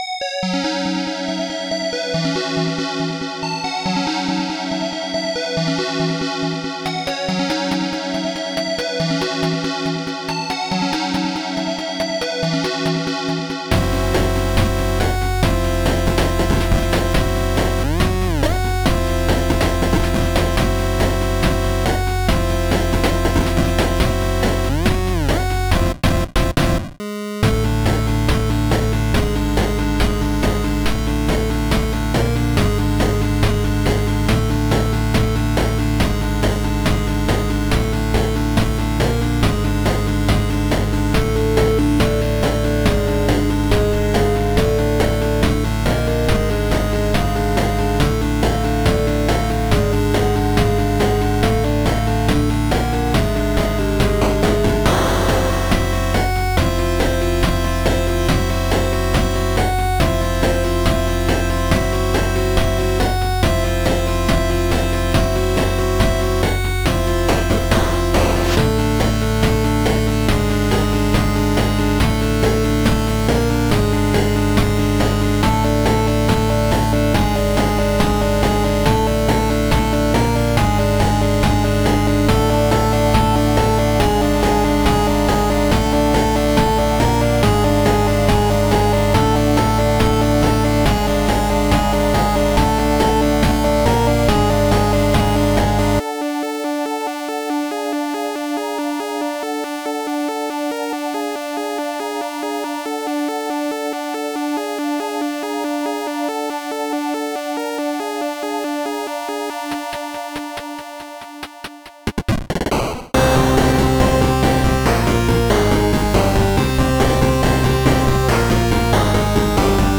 a wordless cry for help